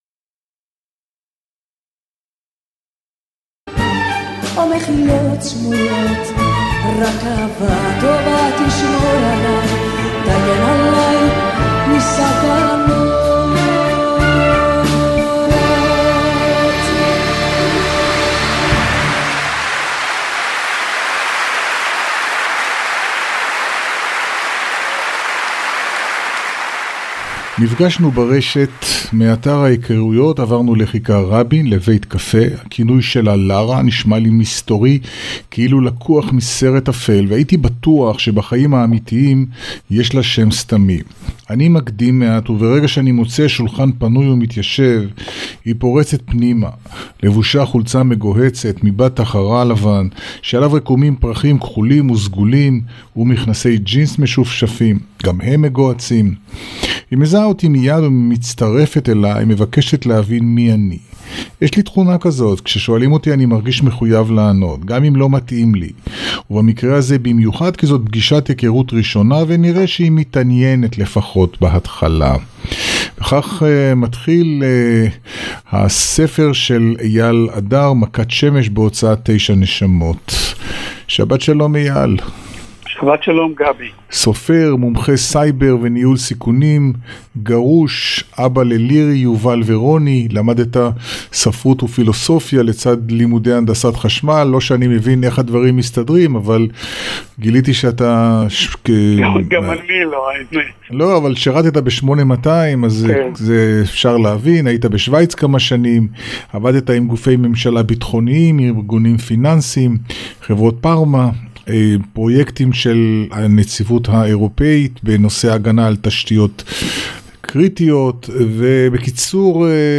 ראיון: רדיו צפון